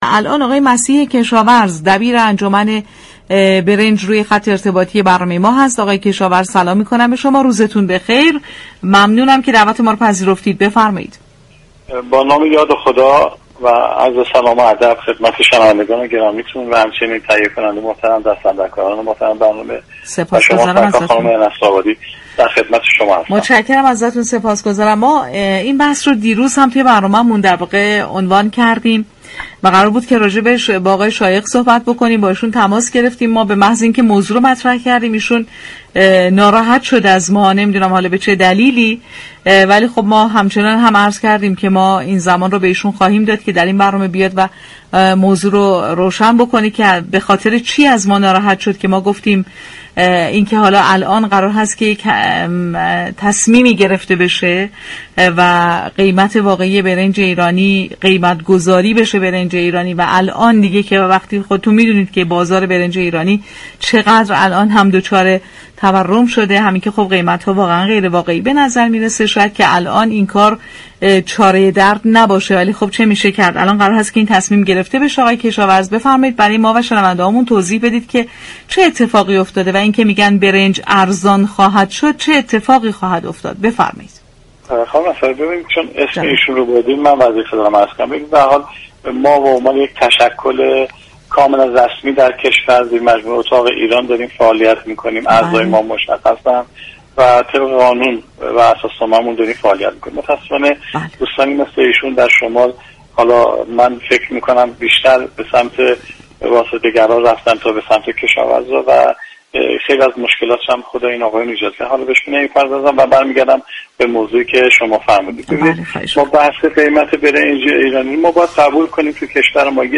در گفت‌وگو با بازار تهران رادیو تهران